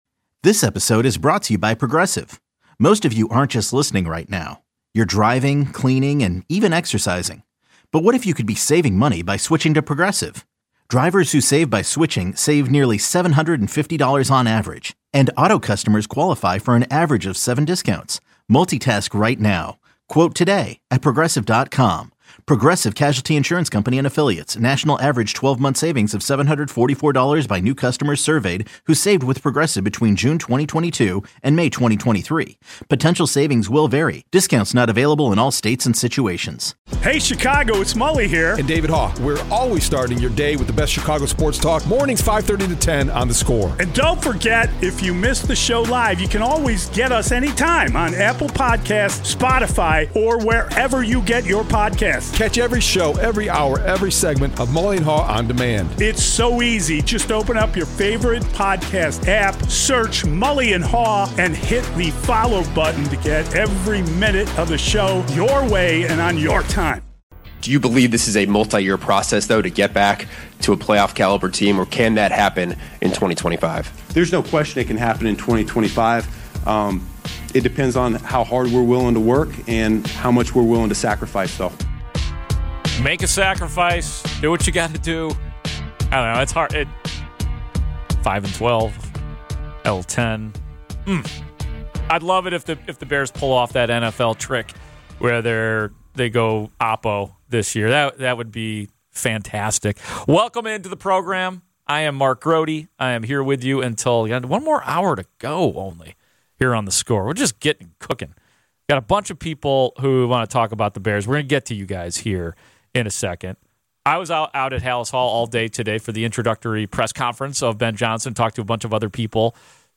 Download - NFL Draft reaction: Ryan Poles press conference (Hour 4) | Podbean